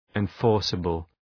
Προφορά
{ın’fɔ:rsəbəl}